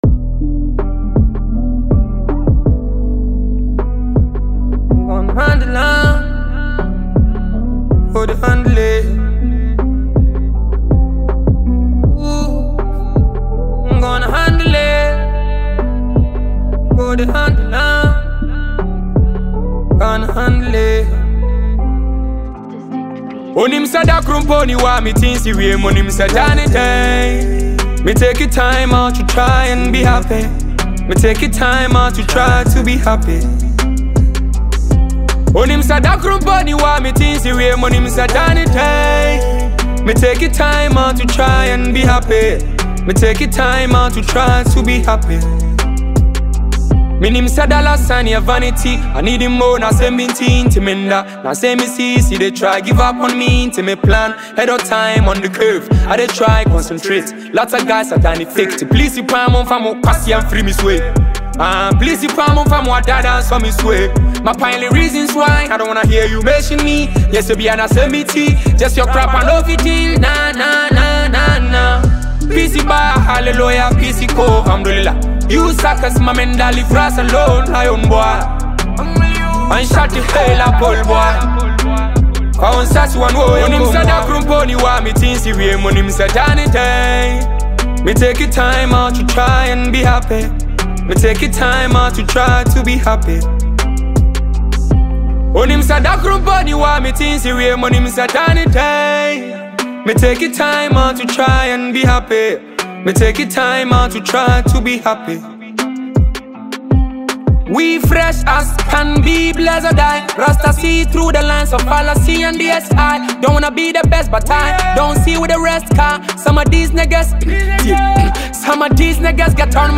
a multifaceted Nigerian rap virtuoso
fiery song